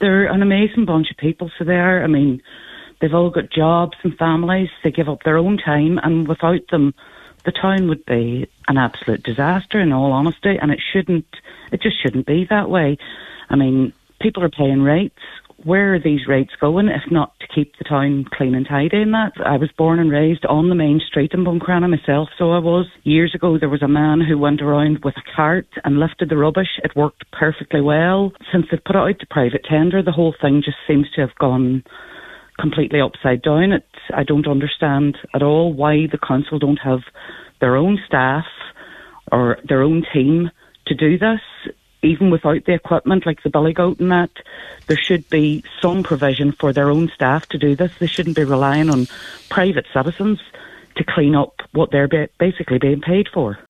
On today’s Nine til Noon Show, several people from Buncrana spoke out in support of the Tidy Towns Committee.